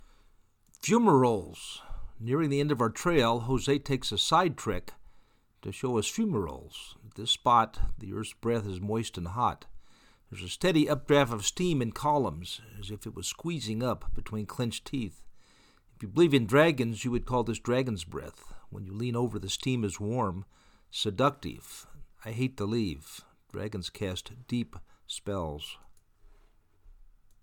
There is a steady updraft of steam in columns as if it was squeezing up between clenched teeth. If you believe in dragon’s, you would call this dragon’s breath.
fumeroles-3.mp3